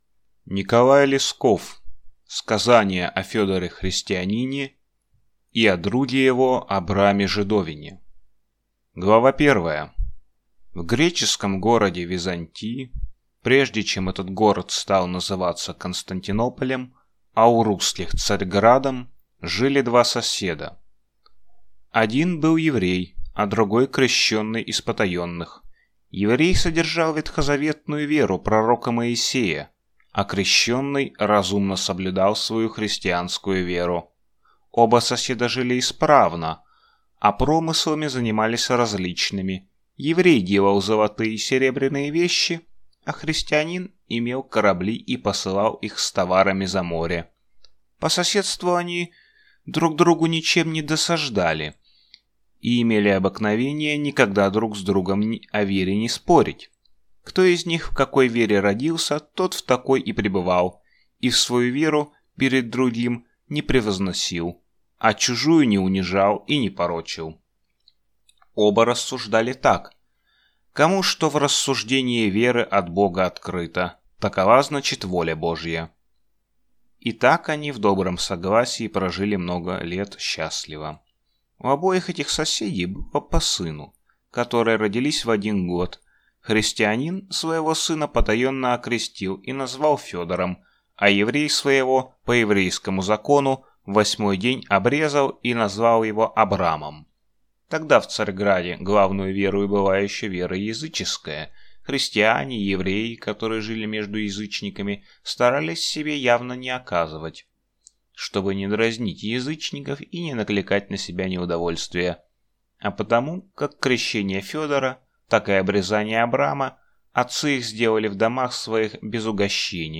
Аудиокнига Сказание о Федоре-христианине и о друге его Абраме-жидовине | Библиотека аудиокниг